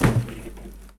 Abrir la puerta de un congelador
Cocina
Sonidos: Acciones humanas
Sonidos: Hogar